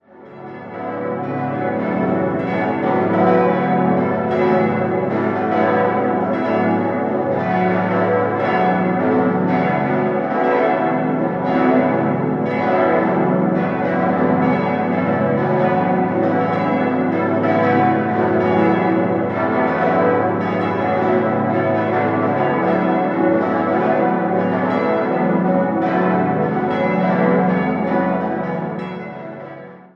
Das achtstimmige Geläute gehört zu den größten Gießleistungen von Karl Czudnochowsky und ist das tontiefste Glockenensemble der Diözese Augsburg. Trotz der Aufhängung in der offenen Glockenstube empfindet man die Glocken in Kirchennähe nicht als zu laut oder direkt. Die große Hosannaglocke ist in Euphon-, die sieben kleineren in Bronzelegierung gegossen.